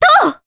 Ophelia-Vox_Attack3_jp.wav